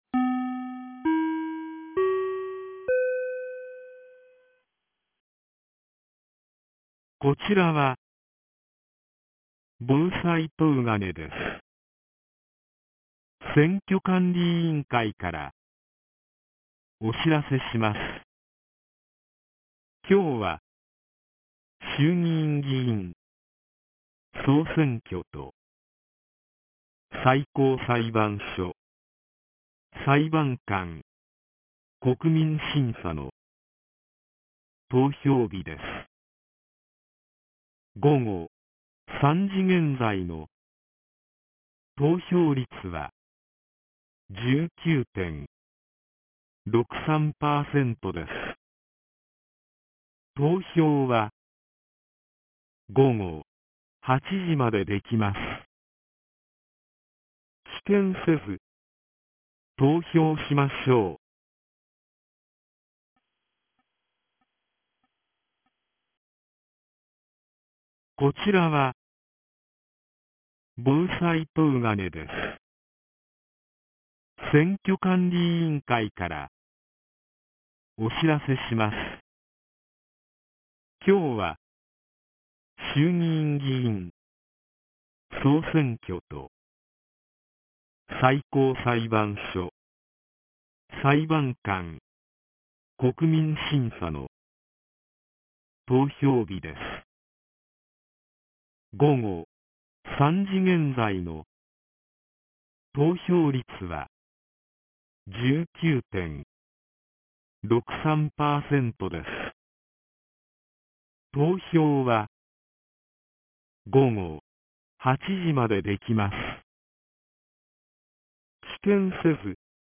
2024年10月27日 15時12分に、東金市より防災行政無線の放送を行いました。